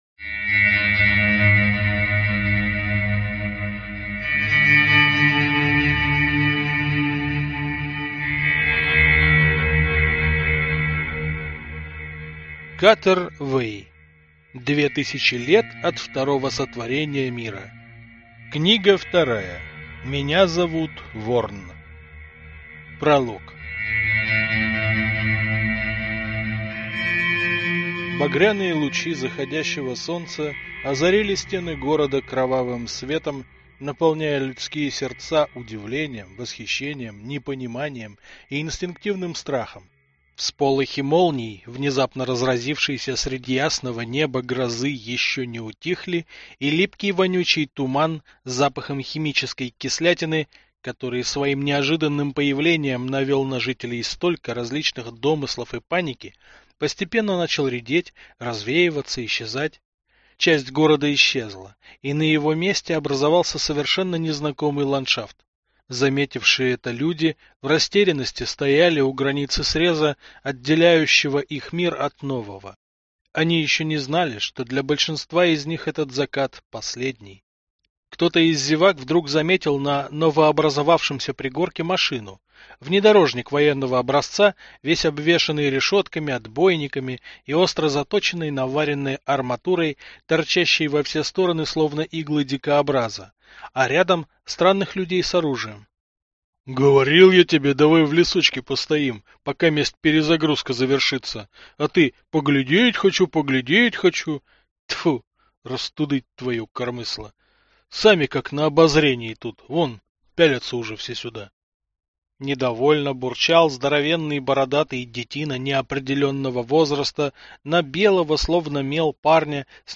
Aудиокнига
Читает аудиокнигу